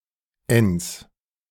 The Enns (German pronunciation: [ɛns]
De-Enns.ogg.mp3